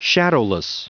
Prononciation du mot shadowless en anglais (fichier audio)
Prononciation du mot : shadowless